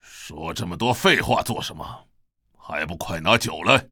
文件 文件历史 文件用途 全域文件用途 Hartz_fw_02.ogg （Ogg Vorbis声音文件，长度3.9秒，104 kbps，文件大小：50 KB） 源地址:地下城与勇士游戏语音 文件历史 点击某个日期/时间查看对应时刻的文件。